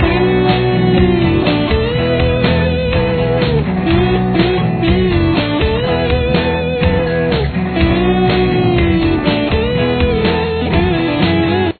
Lead Guitar
Here’s the lead with the backing band: